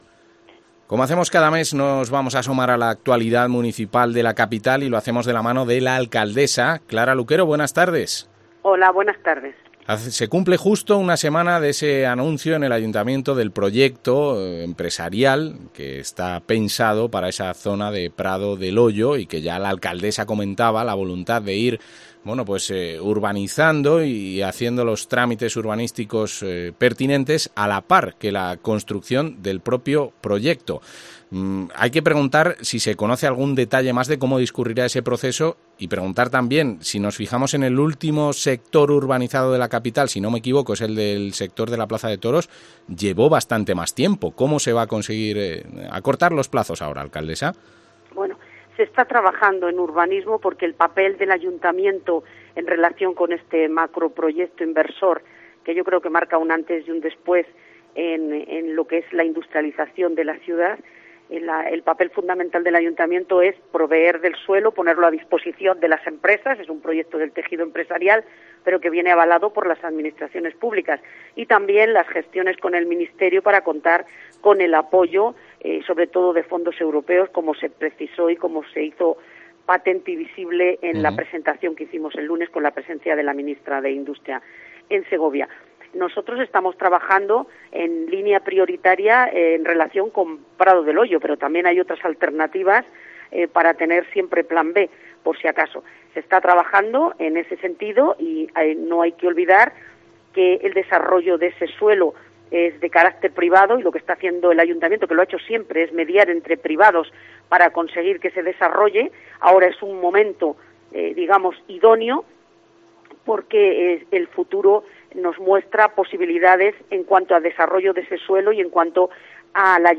Entrevista a la alcaldesa de Segovia, Clara Luquero